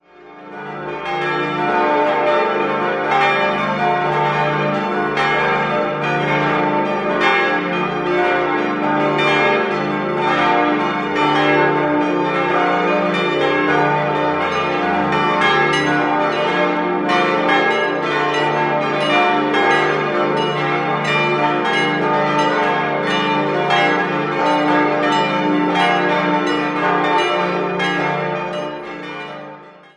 7-stimmiges Geläute: d'-f'-g'-a'-c''-d''-e''
Ein überaus prächtiges und aufgrund der Glockenanzahl höchst bemerkenswertes Geläute, das im Umkreis seinesgleichen sucht. Die kleine Sterbeglocke in der Turmlaterne läutet normalerweise nicht mit den anderen zusammen. In der zweiten Hälfte des Tonbeispieles gesellt sie sich jedoch zu den sieben Schwestern noch mit dazu.